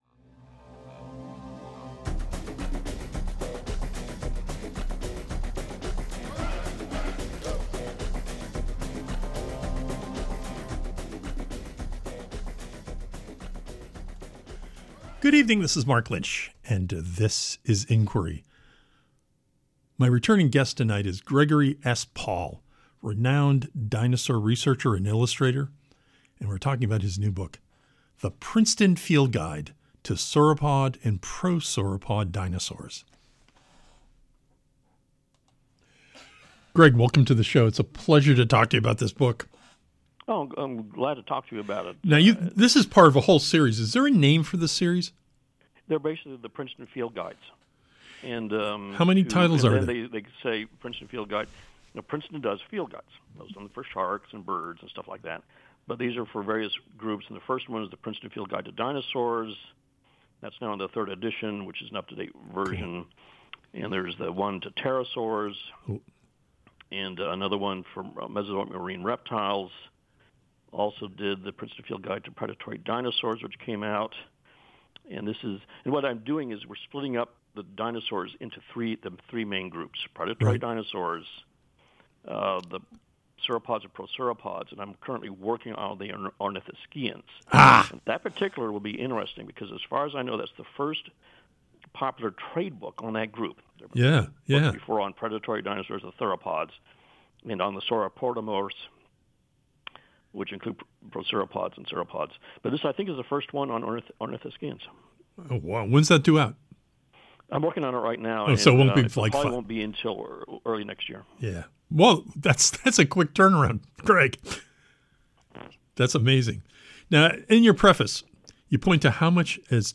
Tonight, on Inquiry, we welcome back renowned dinosaur researcher and illustrator GREGORY S. PAUL to talk about his new book, THE PRINCETON FIELD GUIDE TO SAUROPOD AND PROSAUROPOD DINOSAURS.